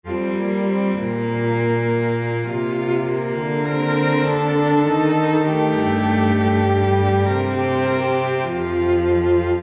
Tag: 100 bpm Ambient Loops Strings Loops 1.62 MB wav Key : Unknown